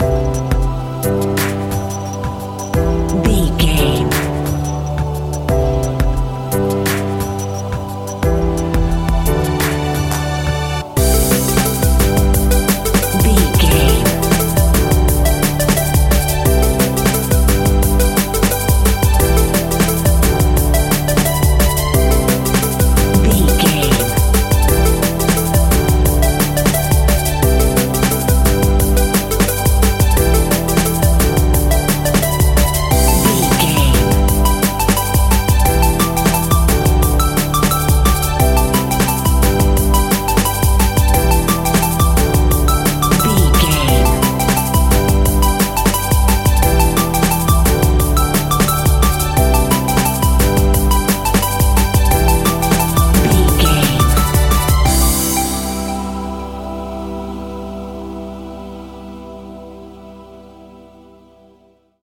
Ionian/Major
Fast
groovy
uplifting
bouncy
cheerful/happy
futuristic
drums
synthesiser
electronic
sub bass
synth leads
synth bass